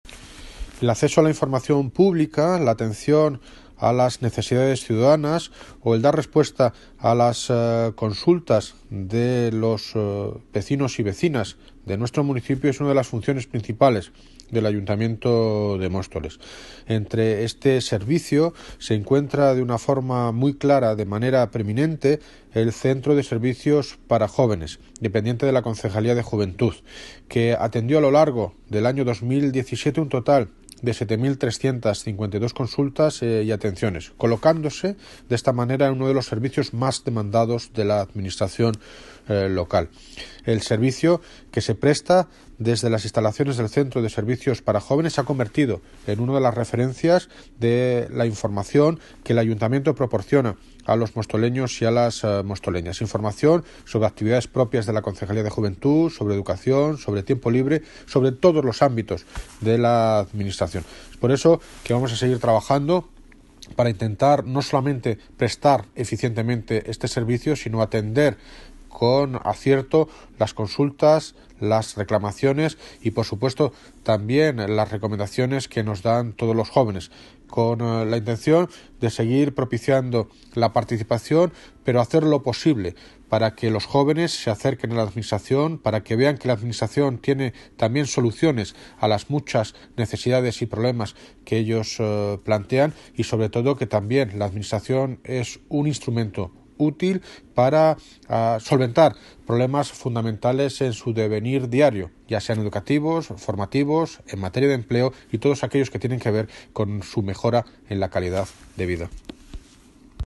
Audio - David Lucas (Alcalde de Móstoles) Sobre Juventud